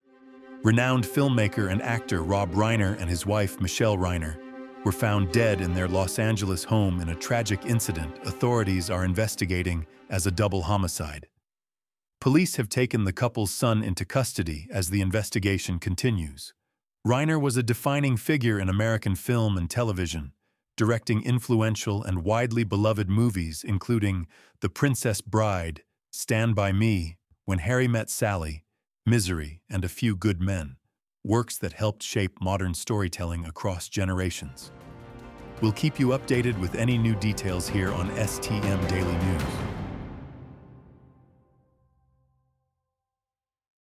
A brief news update on the tragic death of filmmaker Rob Reiner, outlining what is known so far and reflecting on the lasting impact of his work on American film.